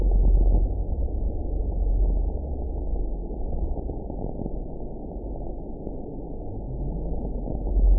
event 919967 date 01/31/24 time 10:44:45 GMT (1 year, 4 months ago) score 6.68 location TSS-AB08 detected by nrw target species NRW annotations +NRW Spectrogram: Frequency (kHz) vs. Time (s) audio not available .wav